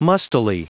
Prononciation du mot mustily en anglais (fichier audio)
Prononciation du mot : mustily